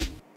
Layer Snare (Power).wav